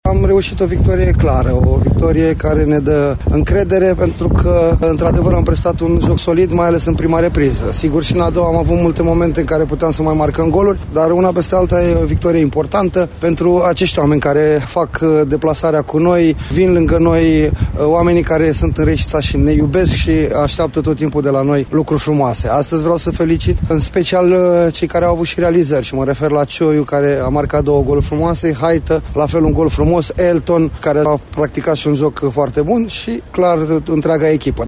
La final, antrenorul roș-negrilor, Flavius Stoican, a vorbit despre cum a gândit meciul din sudul țării: